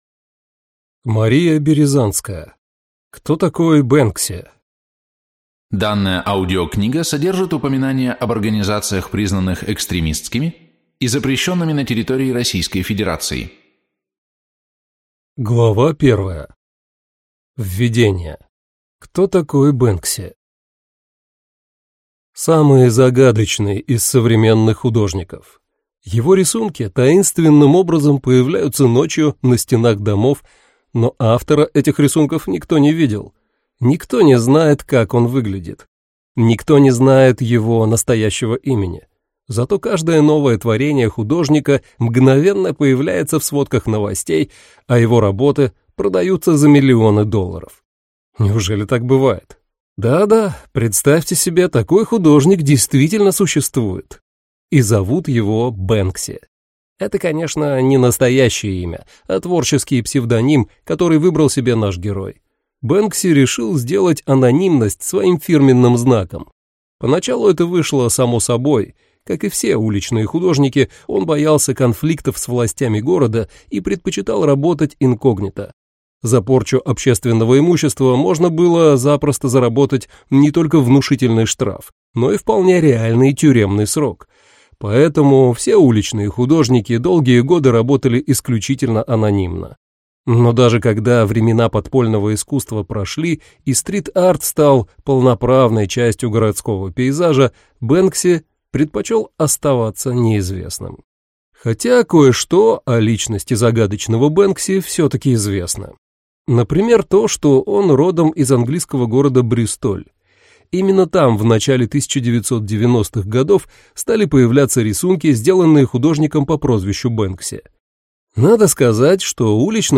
Аудиокнига Кто такой Бэнкси?